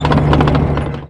tank-engine-load-rotation-2.ogg